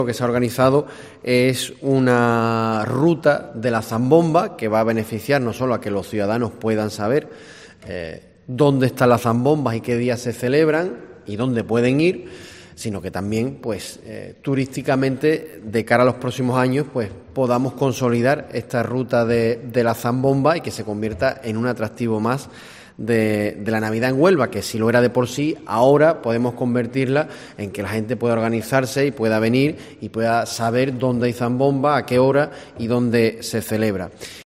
Nacho Molina, concejal de Cultura del Ayuntamiento de Huelva